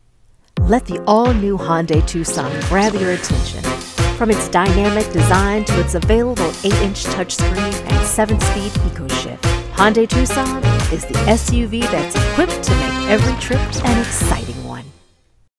Commercial
Hyundai-Tucson-Commercial-Sample.mp3